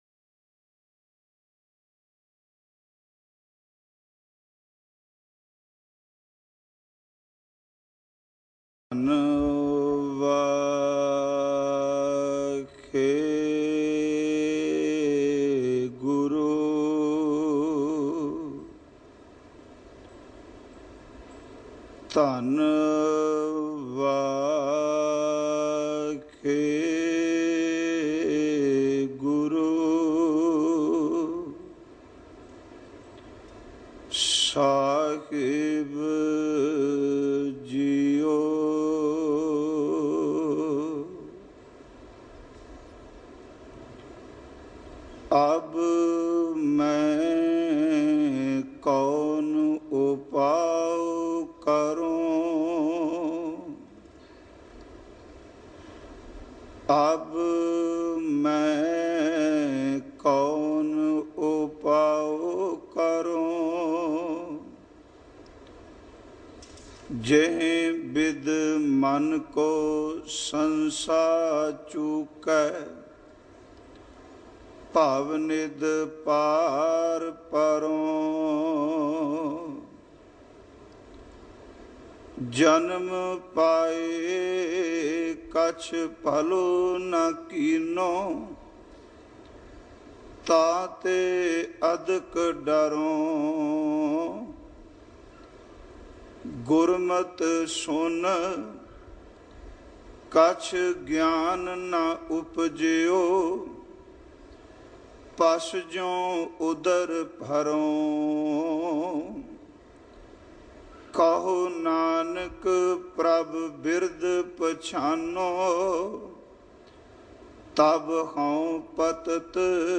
Listen and Download Katha